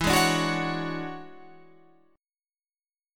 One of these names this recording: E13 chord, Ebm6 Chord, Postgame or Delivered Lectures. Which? E13 chord